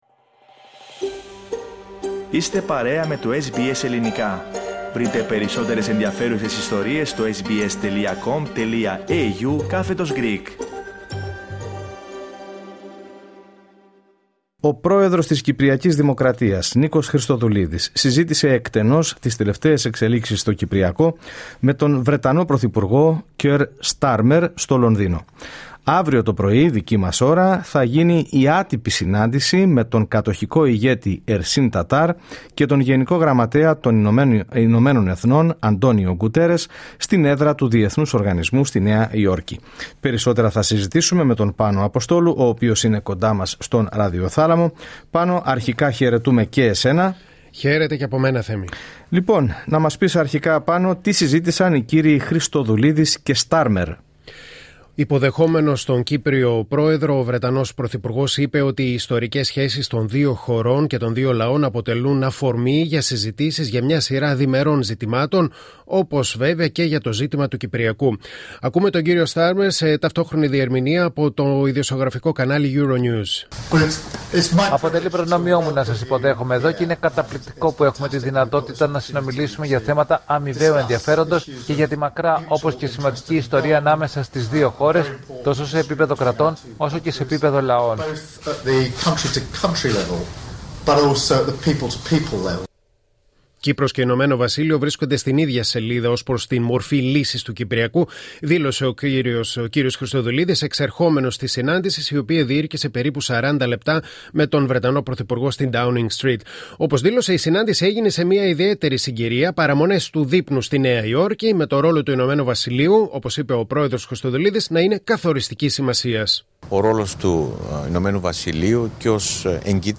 Ακούμε τον κ. Στάρμερ σε ταυτόχρονη διερμημεία από το ειδησεογραφικό κανάλι Euronews.